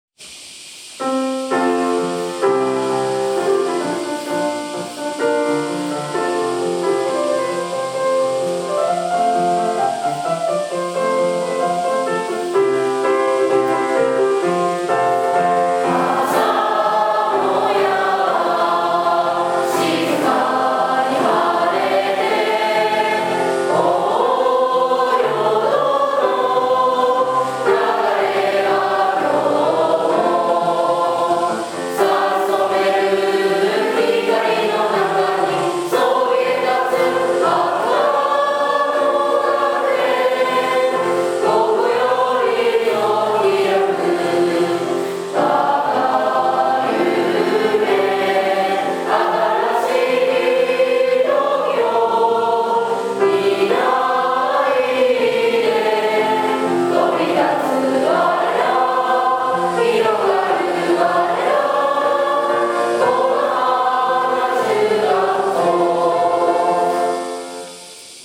本日、終業式が行われました。
今年度初めて、全学年で歌い上げた校歌です。 セミと奏でるハーモニーをぜひお聴きください。